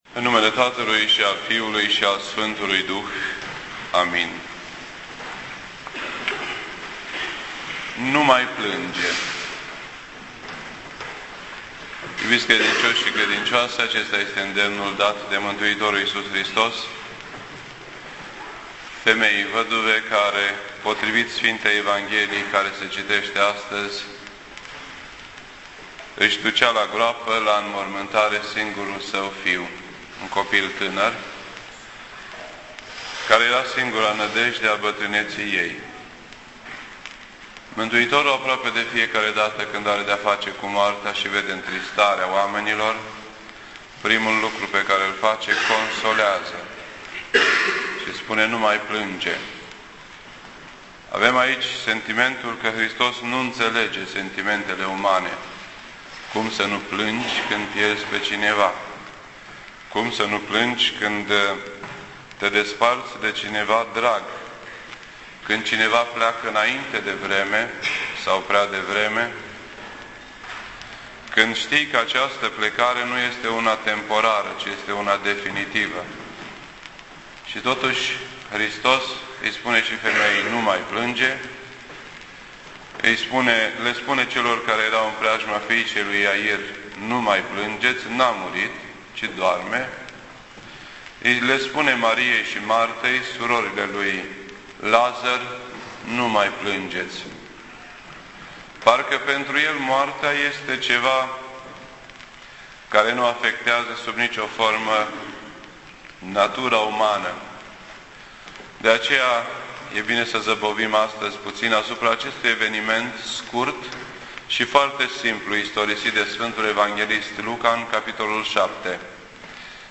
This entry was posted on Sunday, October 18th, 2009 at 6:32 PM and is filed under Predici ortodoxe in format audio.